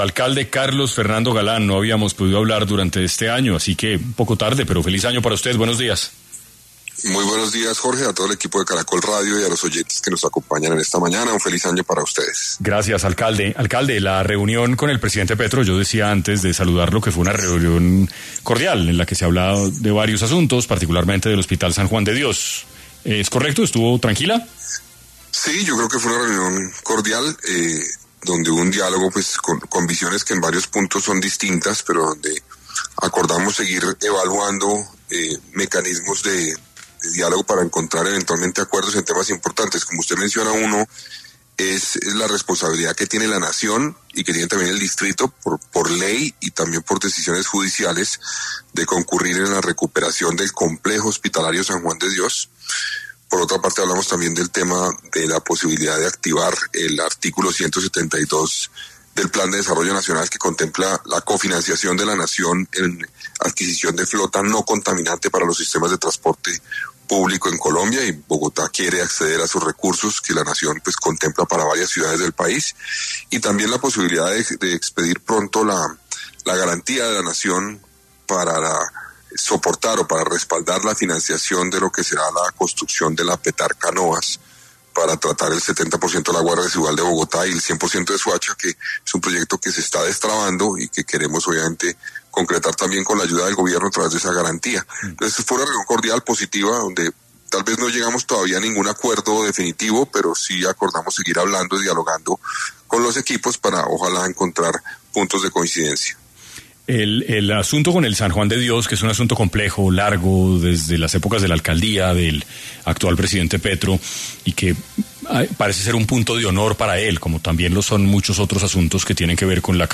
Carlos Fernando Galán, alcalde de Bogotá, habló en 6AM, sobre las conclusiones de la reunión con el presidente Gustavo Petro sobre proyectos de infraestructura en Bogotá